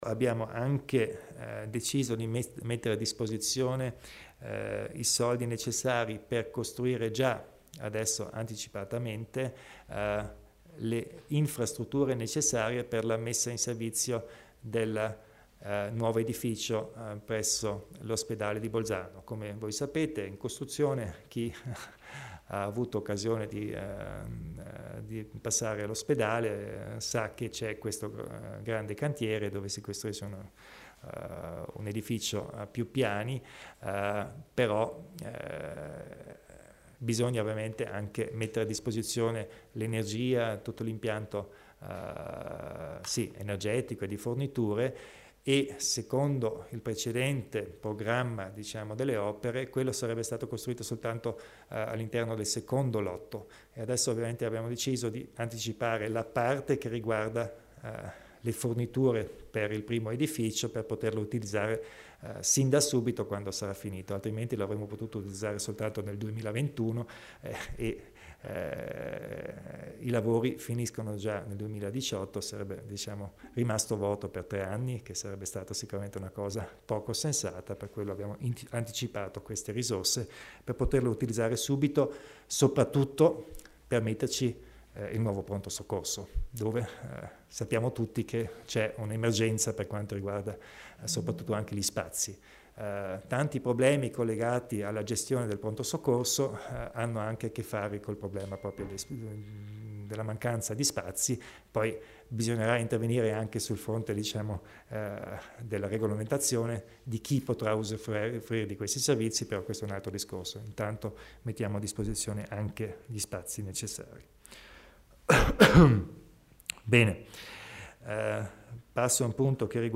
Il Presidente della Provincia elenca gli interventi per il nuovo pronto soccorso